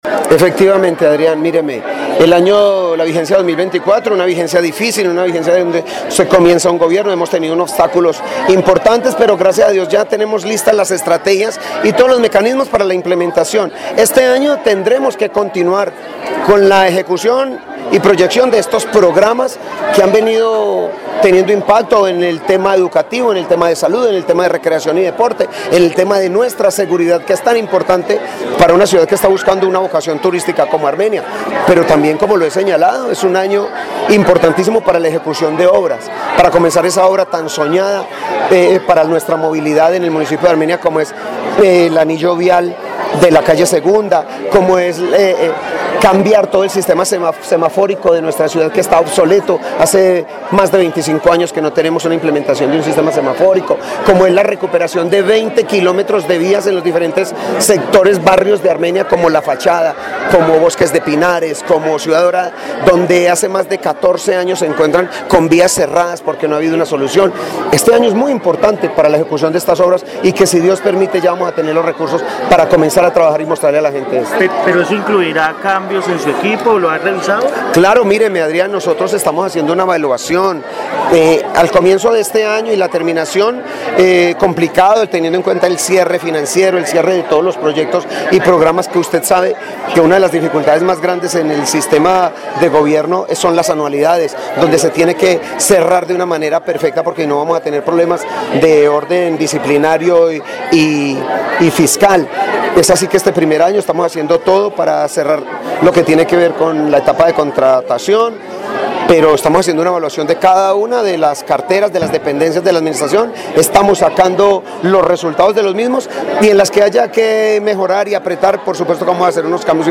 James Padilla García, alcalde de Armenia
En diálogo con Caracol Radio Armenia el alcalde indicó “efectivamente el año la vigencia 2024 una vigencia difícil una vigencia de donde se comienza un gobierno.